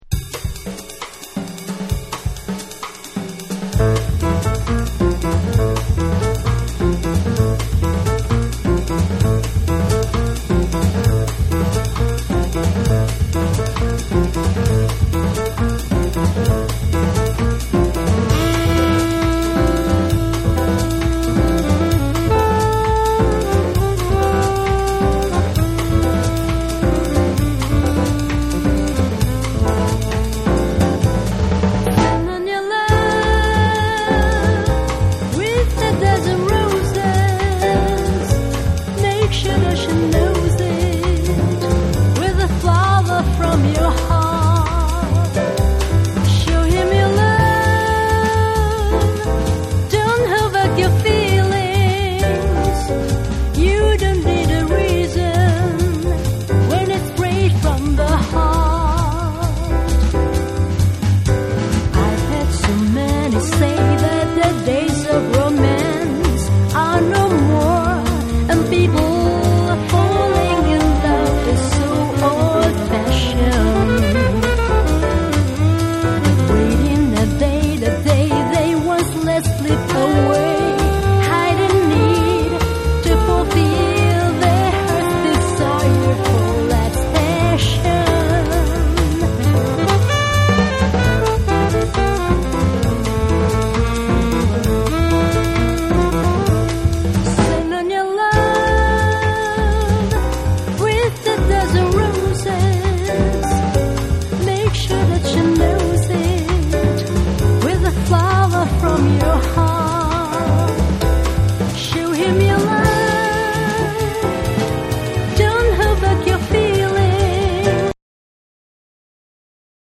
JAPANESE / SOUL & FUNK & JAZZ & etc